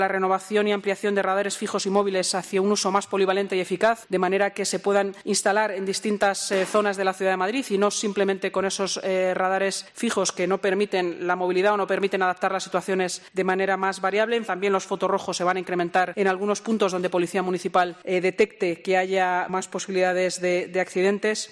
Inmaculada Sanz, delegada de Seguridad del Ayuntamiento de Madrid